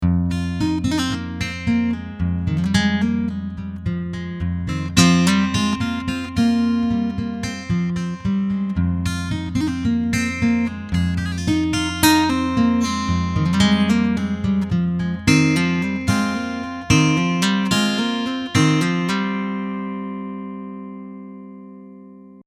All recordings were tracked straight into Logic with no additional EQ or processing using the USB out on the Pod X3.
Out of the box – I put a “parlor” model (based on a Martin size 5 “Parlor”) through an acoustic setting in my Line 6 Pod:
The first mp3s are excerpts of a solo finger style piece of mine that’s currently untitled.  55 refers to the bpm.  If you play each mp3 – you can hear the timbral difference is the virtual mic placement.
Parlor mic far left 55
parlor-mic-far-left-55.mp3